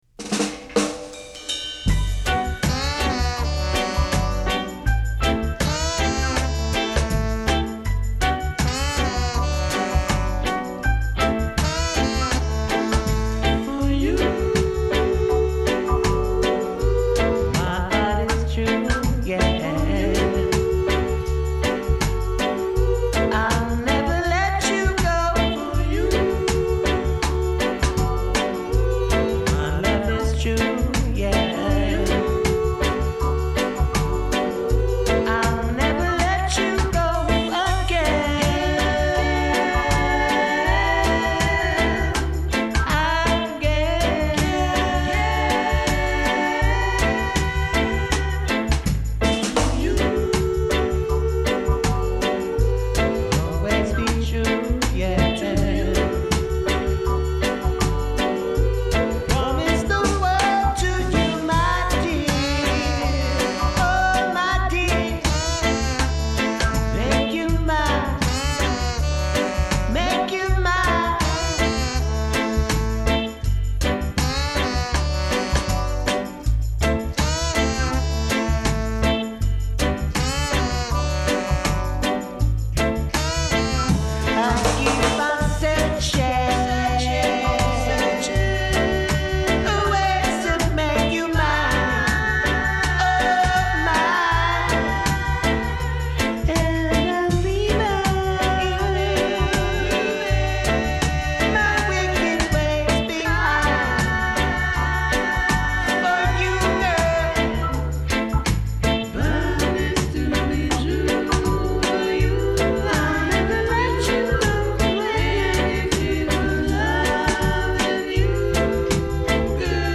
Genre : Funk, Soul